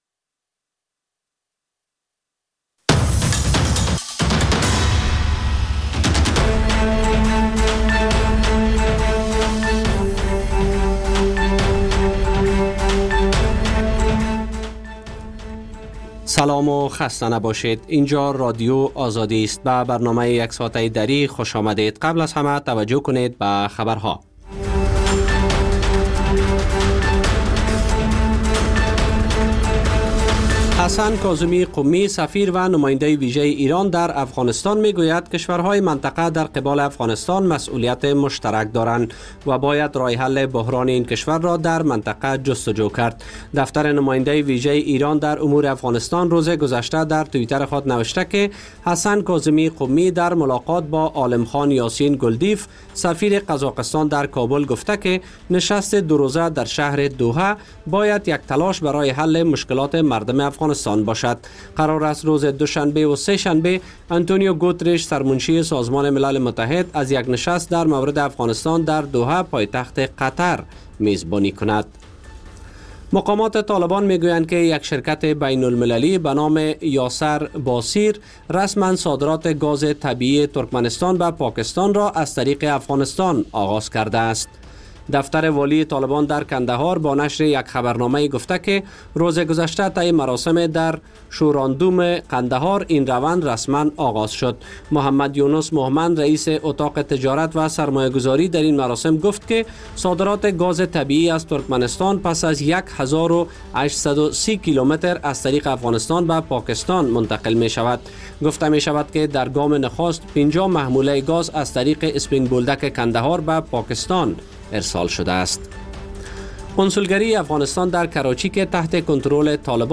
مجله خبری صبح‌گاهی